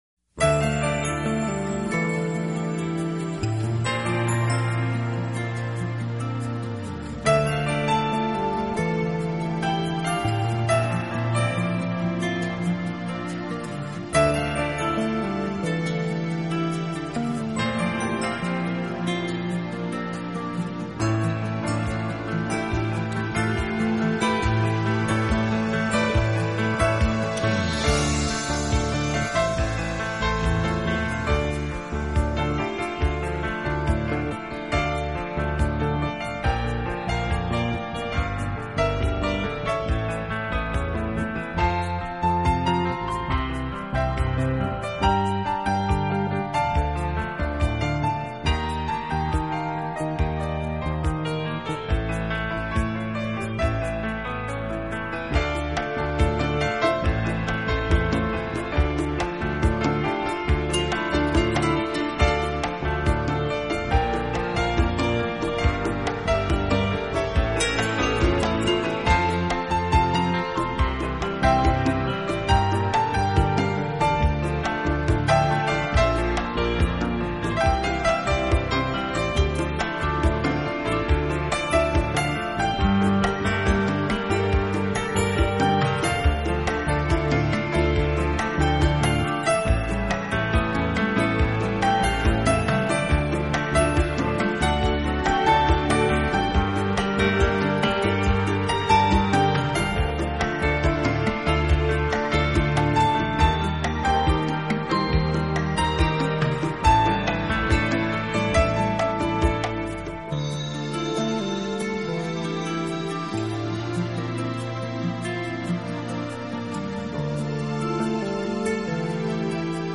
新世纪纯音乐
专辑类型：New Age
轻快的节奏交织着双簧管流畅优雅的旋律，吉他的精彩演奏更添加了一份醉意！
尽致，纯净透彻，旋律优雅，美轮美奂的意境令人叹为观止。